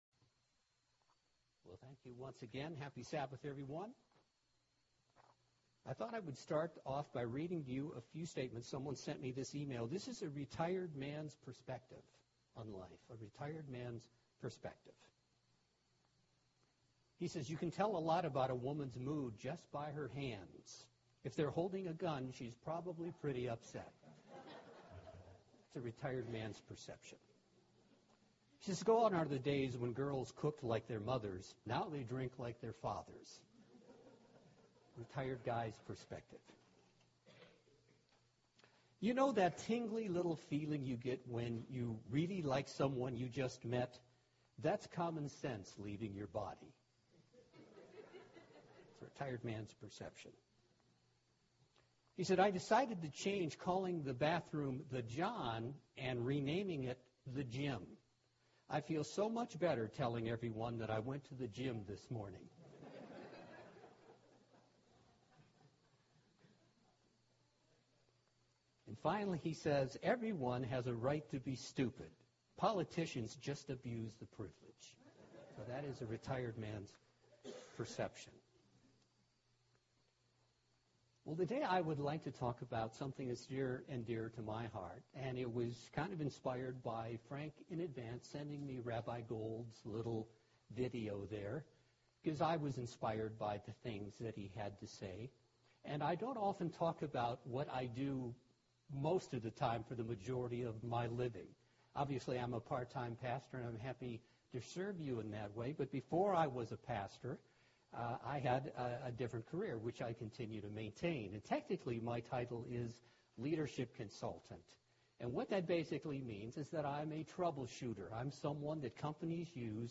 This is the first of a multipart sermon on the importance of personal leadership.